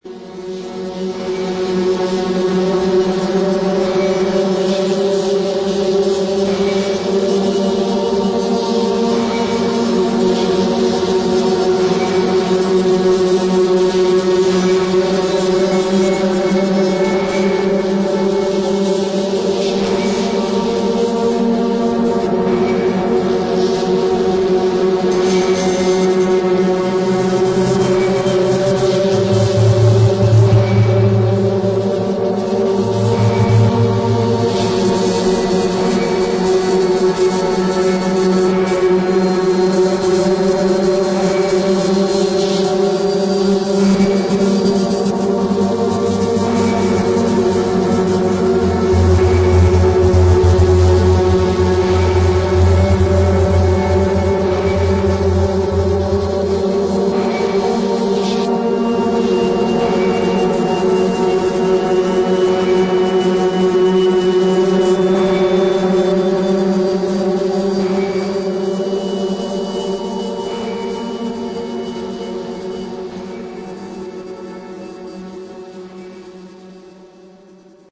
[ TECHNO / INDUSTRIAL ]